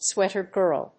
アクセントswéater gìrl